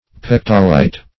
Meaning of pectolite. pectolite synonyms, pronunciation, spelling and more from Free Dictionary.
Search Result for " pectolite" : The Collaborative International Dictionary of English v.0.48: Pectolite \Pec"to*lite\ (p[e^]k"t[-o]*l[imac]t), n. [L. pecten a comb + -lite.]